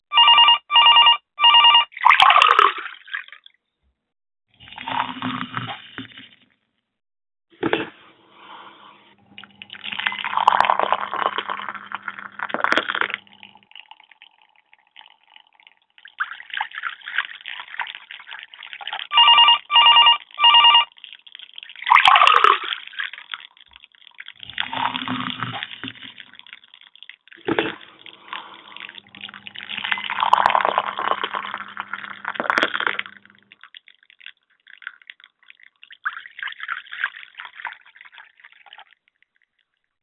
صوت صب القهوة – تغاريد البادية
الالات واصوات